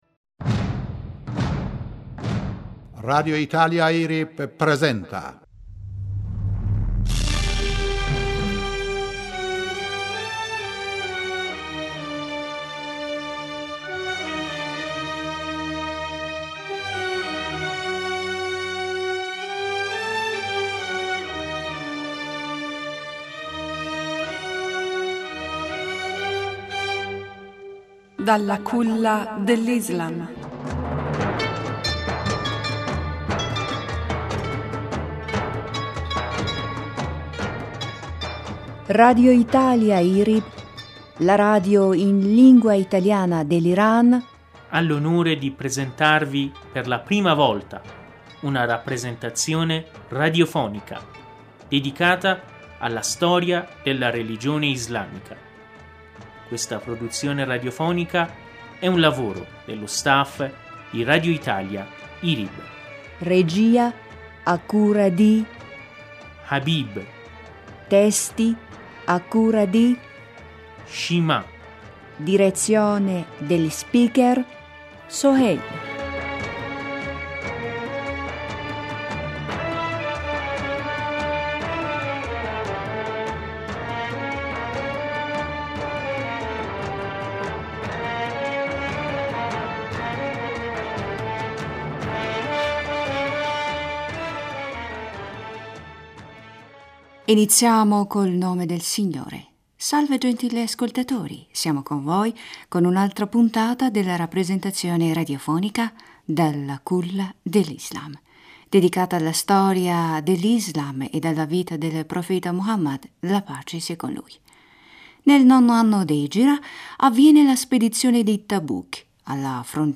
Storia dell'Islam" (129) (ASCOLTA AUDIO) Ago 22, 2016 07:05 CET Scarica PARSTODAY-Iniziamo col nome del Signore, salve gentili ascoltatori siamo con voi con un’altra puntata della rappresentazione radiofonica “Dalla Culla dell’Islam”, dedicata alla storia dell’islam ed alla vita del Profeta Muhammad (as). Nel nono anno d’egira avviene la spedizione di Tabuk alla frontiera dell’Hijàz e di Sham (odierna Siria) perché correva voce che l’Imperatore bizantino Eraclio vi avesse concentrato delle truppe costituite da Arabi e bizantini cristiani.